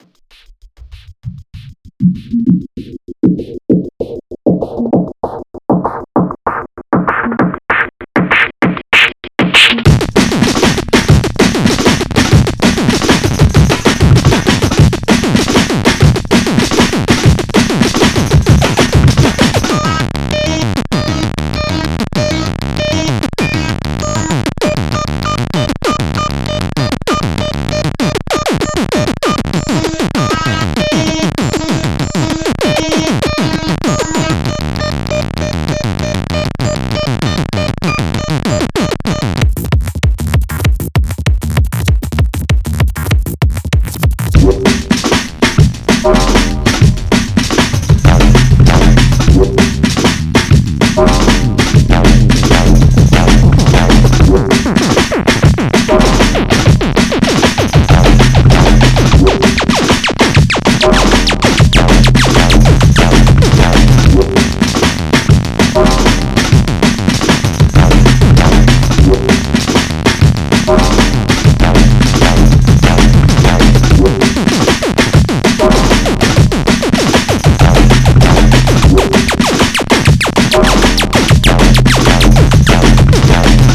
warning: squeaky sound design
glitchy noise bird
Music / Techno
hardcore breakbeat digital noise discordant jungle dnb drumnbass breaks